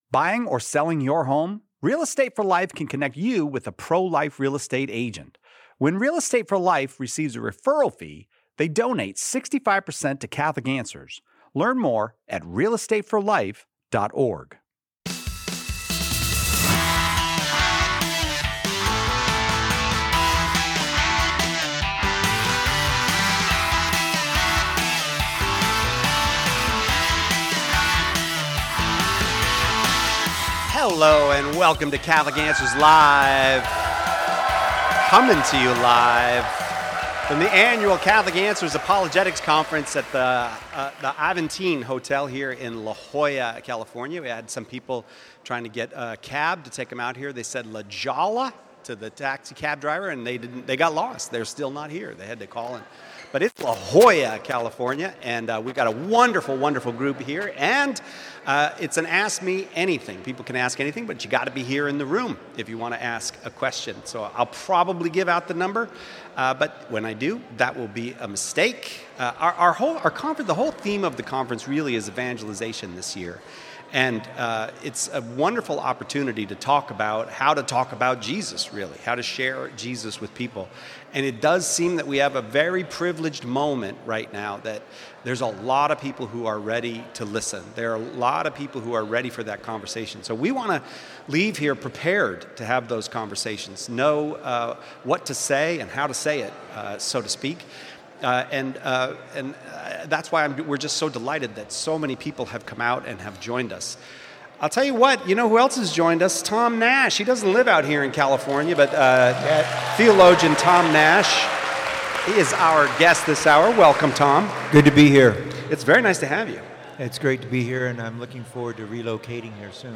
Live from the Catholic Answers Conference